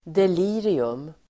Ladda ner uttalet
delirium substantiv, delirium Uttal: [del'i:rium] Böjningar: deliriet Definition: sinnesförvirring framkallad av alkohol (mental disturbance caused by alcohol) Exempel: delirium tremens (delirium tremens)